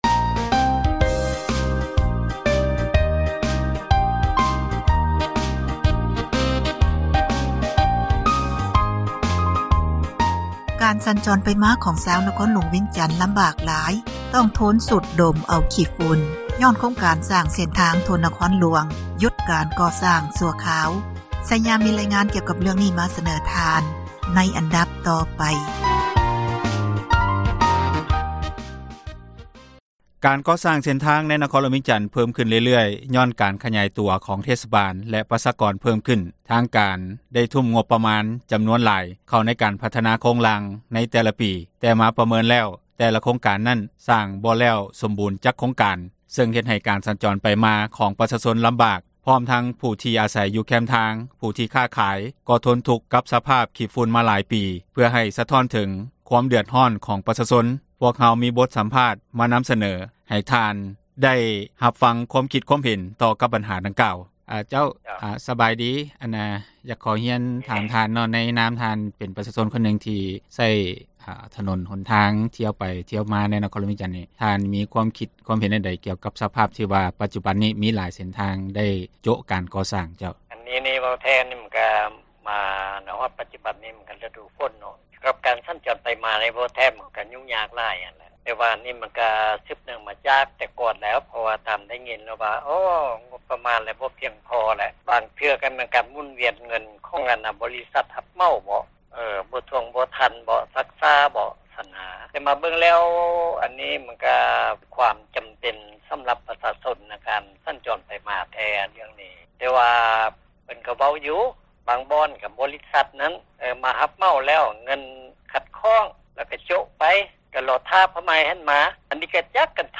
ເຊິ່ງເຮັດໃຫ້ການສັນຈອນໄປມາ ຂອງປະຊາຊົນລຳບາກ ພ້ອມທັງຜູ້ທີ່ອາສັຍຢູ່ແຄມທາງ ຜູ້ທີ່ຄ້າຂາຍ ກໍ່ທົນທຸກກັບສະພາບຂີ່ຝຸ່ນມາຫຼາຍປີ ເພື່ອໃຫ້ສະທ້ອນເຖິງ ຄວາມເດຶອດຮ້ອນ ຂອງປະຊາຊົນ ພວກເຮົາມີການສຳພາດ ປະຊາຊົນ ຜູ້ທີ່ໄດ້ສະແດງ ຄວາມຄິດເຫັນ ຕໍ່ບັນຫາ ດັ່ງກ່າວ.